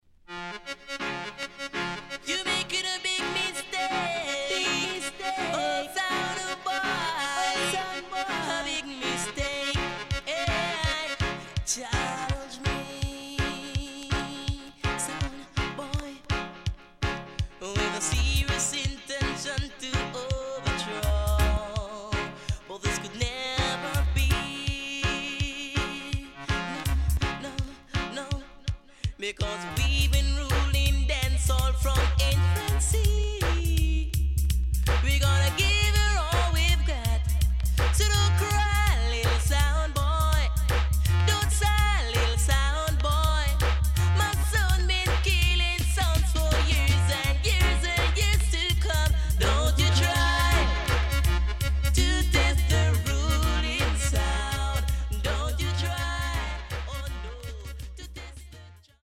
HOME > Back Order [DANCEHALL LP]